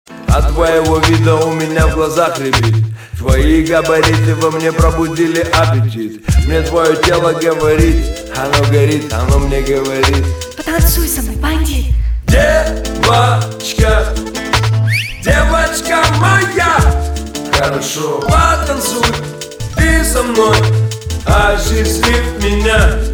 свист
Хип-хоп
русский рэп
медленные